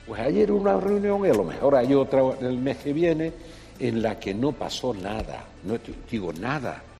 Declaraciones de Felipe González sobre que no paso nada en Moncloa